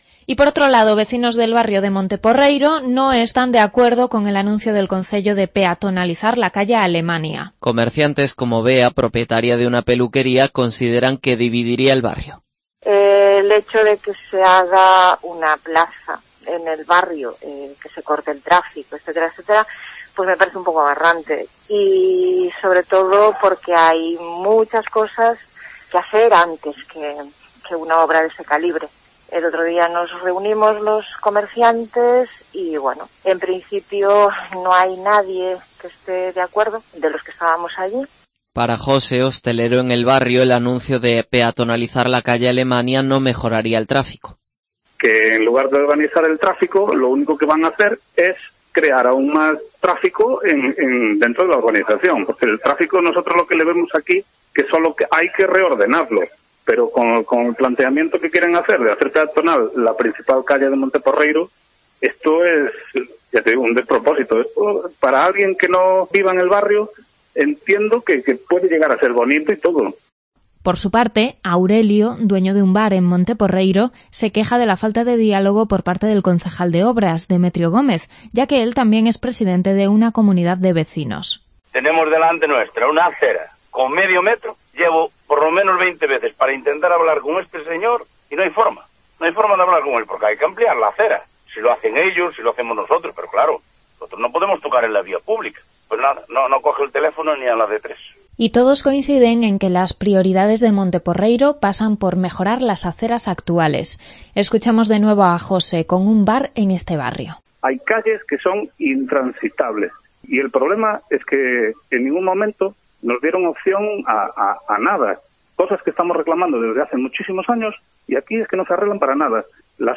Declaraciones de comerciantes y hosteleros de Monte Porreiro